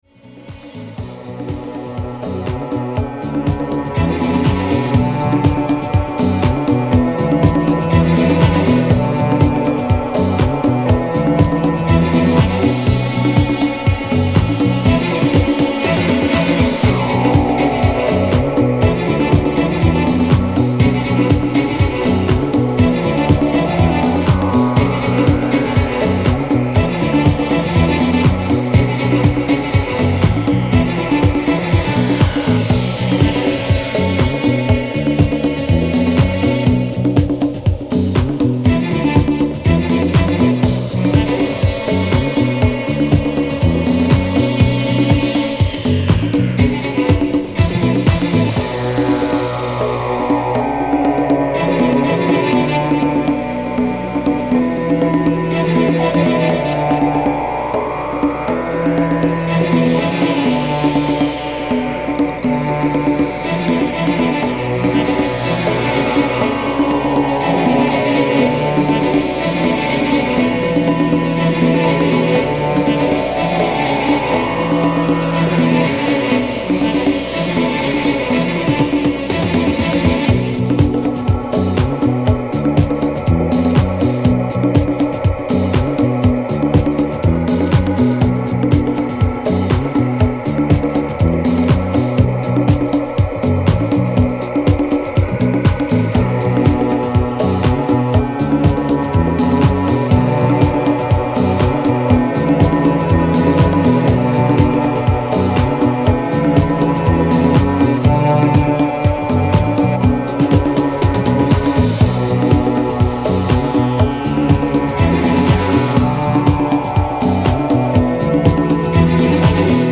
HOUSE
空気感は今でも色褪せないです！